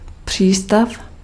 Ääntäminen
France: IPA: [pɔʁ]